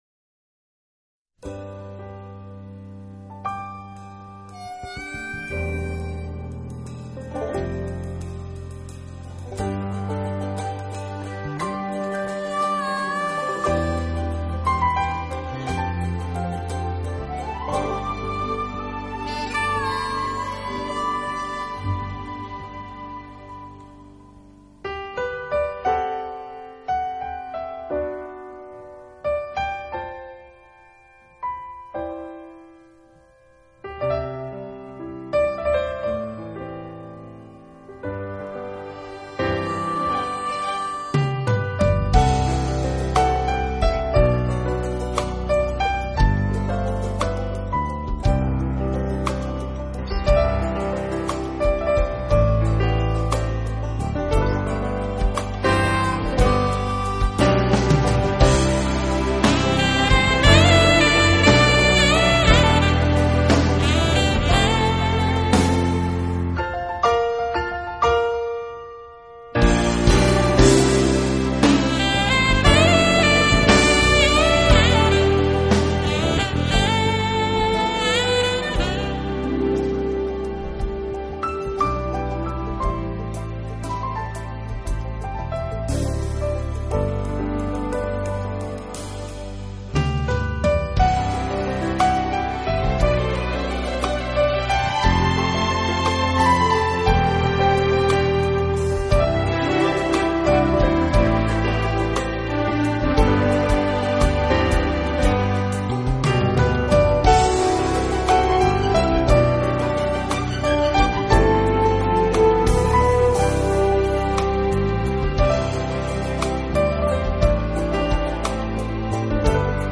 音乐类型：Jazz, Piano
此碟分別於英國和美國兩地錄音